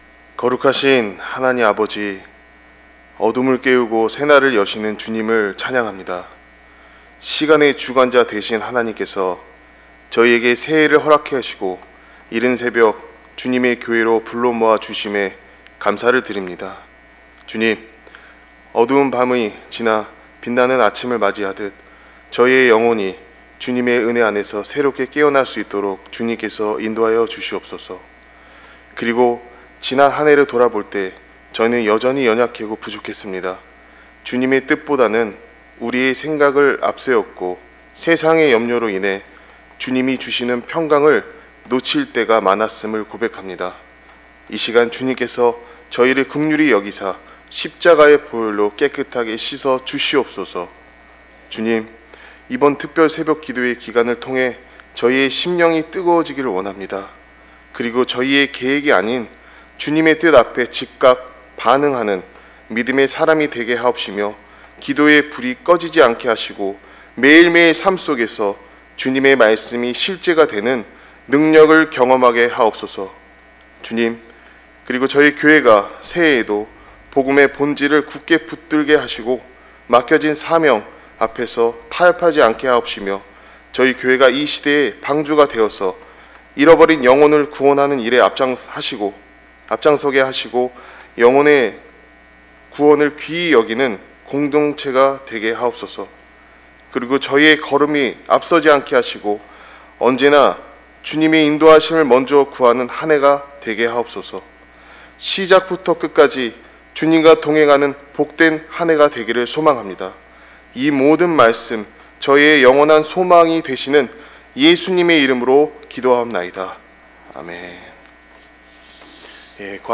신년 특별새벽기도회